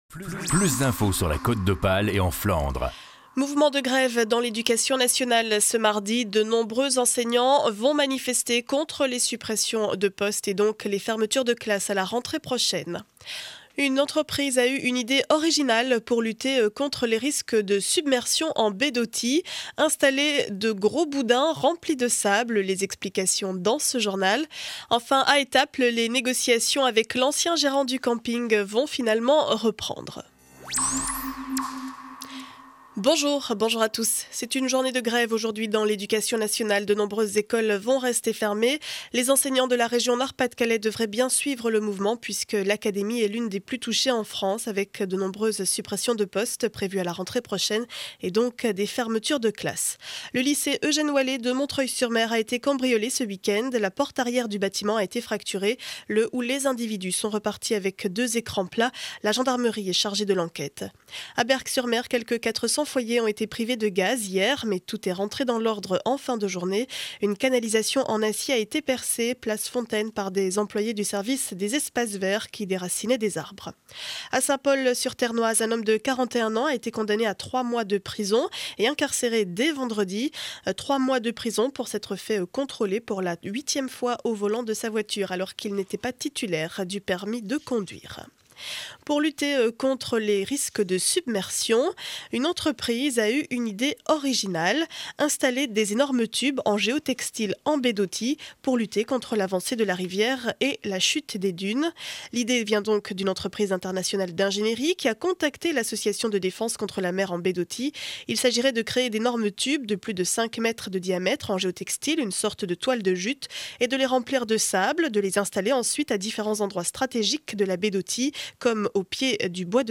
Journal du mardi 31 janvier 2012 7 heures 30 édition du Montreuillois.